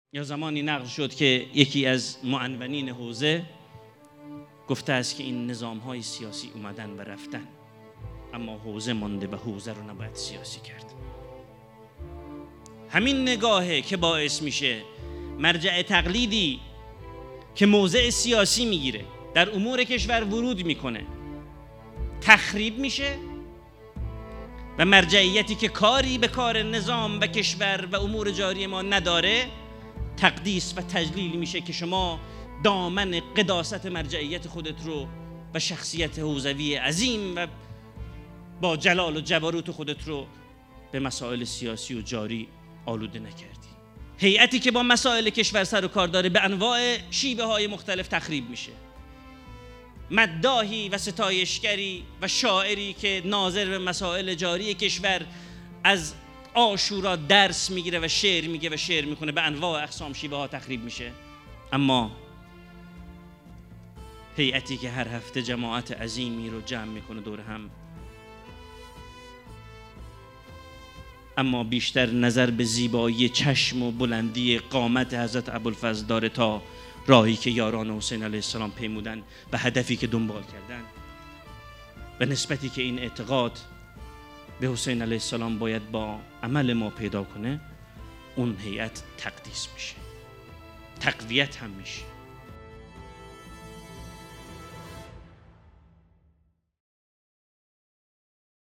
گزیده ای از سخنرانی
نهمین همایش هیأت‌های محوری و برگزیده کشور | شهر مقدس قم - مسجد مقدس جمکران - مجتمع یاوران مهدی (عج)